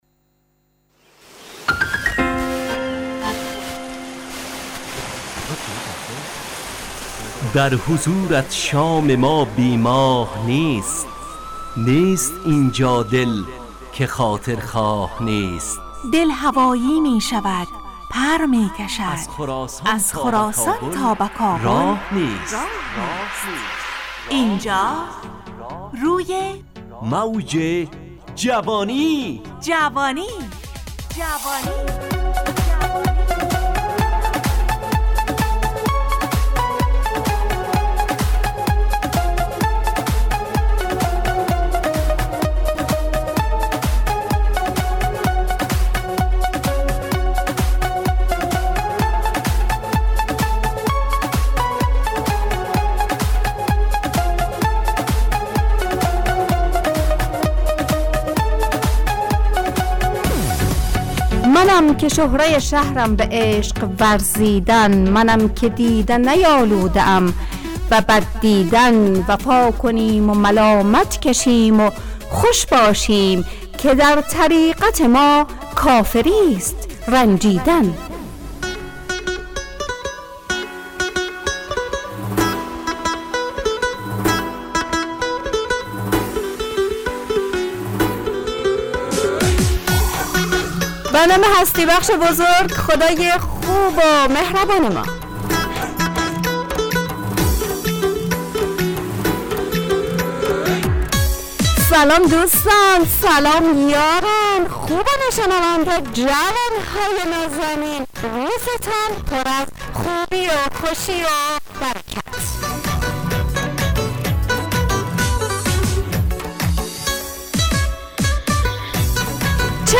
همراه با ترانه و موسیقی مدت برنامه 70 دقیقه . بحث محوری این هفته (آموزگار) تهیه کننده